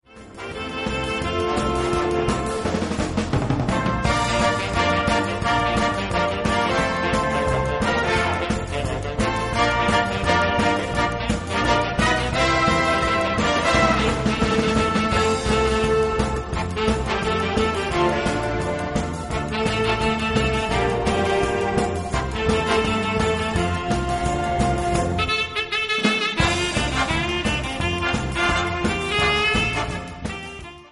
2:17 Minuten Besetzung: Blasorchester Zu hören auf